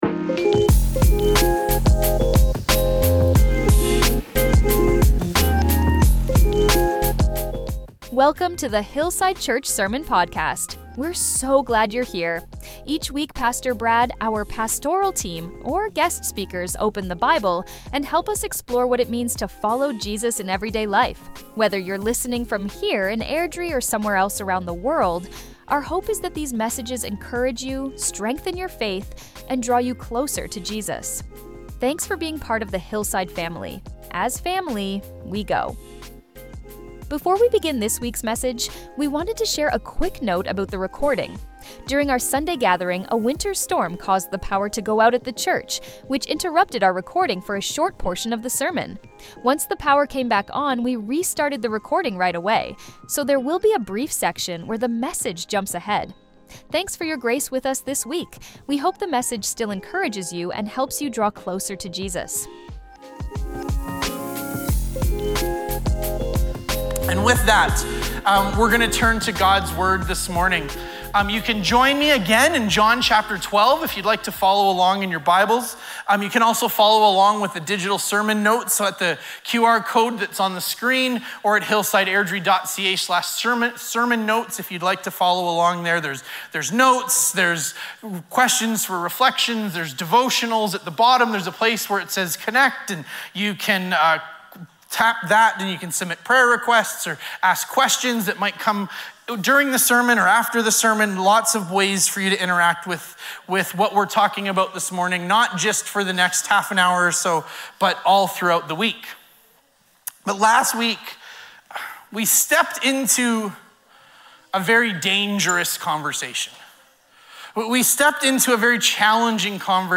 The weekly audio from the Sunday services and as well other teachings and different content from Hillside Church.
This sermon called us to consider what it looks like for our own love for Jesus to pour out freely.
Once power was restored we resumed recording, so youll notice a brief jump in the audio.